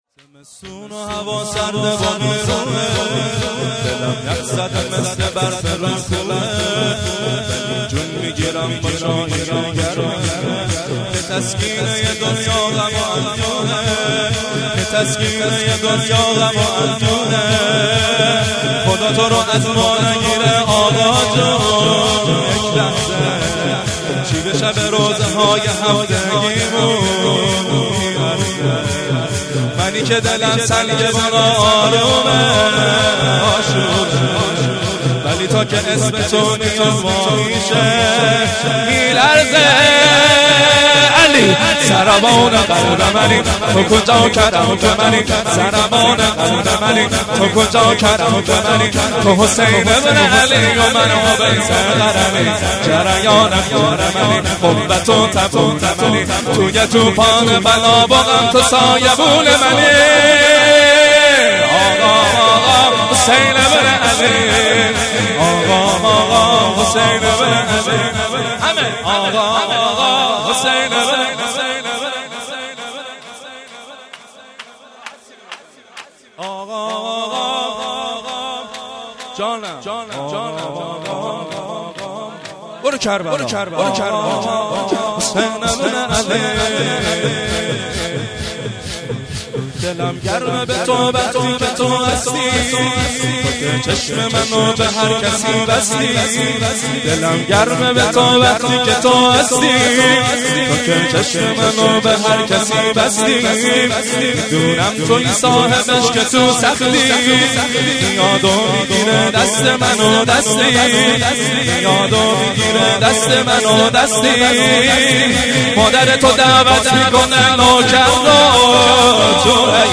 0 0 شور
توسل هفتگی-روضه حضرت زهرا(س)-13 بهمن 1396